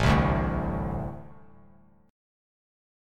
Ambb5 chord